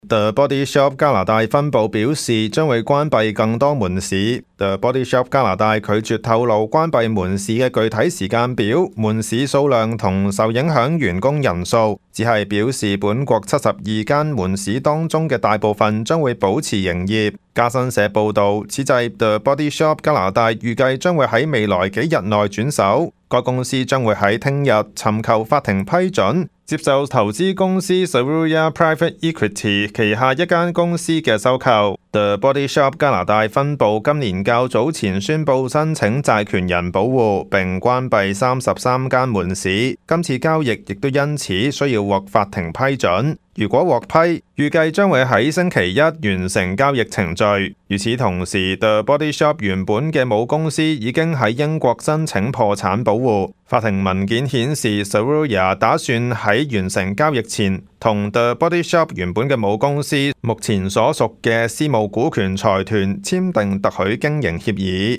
news_clip_21695.mp3